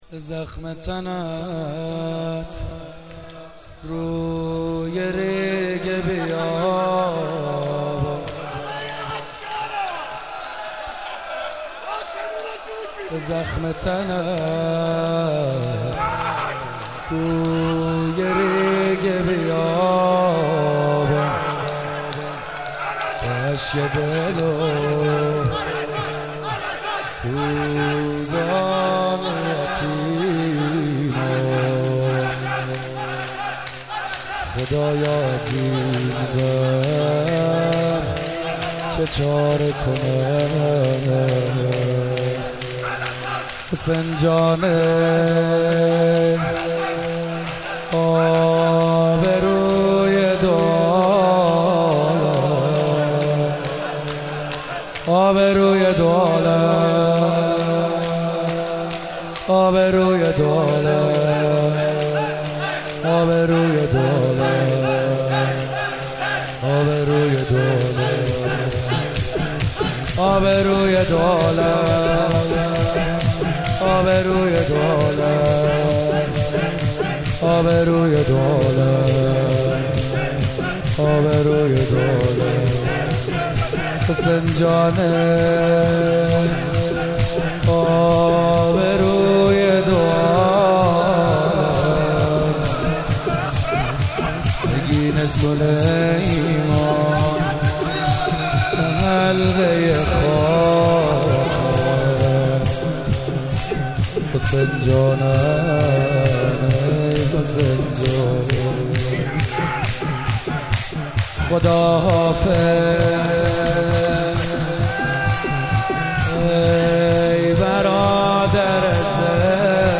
شب عاشورا محرم97 - روضه - بخش سوم